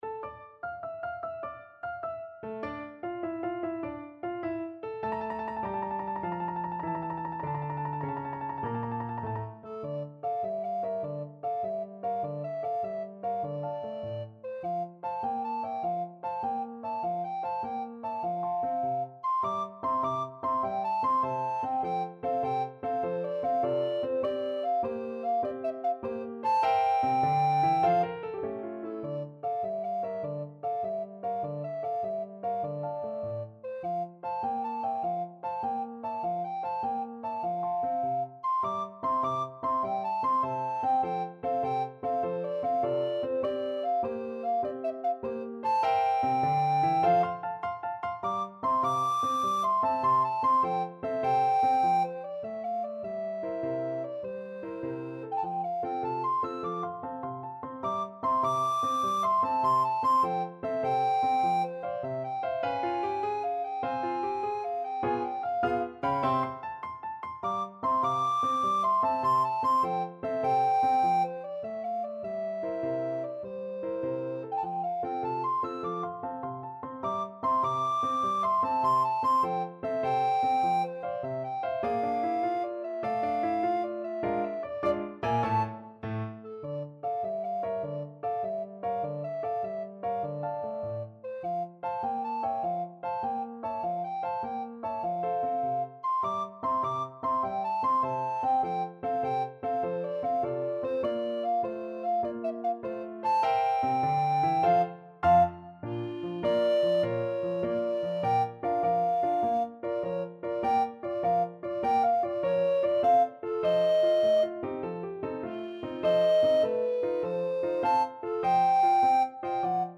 6/8 (View more 6/8 Music)
Allegro moderato . = c.100 (View more music marked Allegro)
Pop (View more Pop Alto Recorder Music)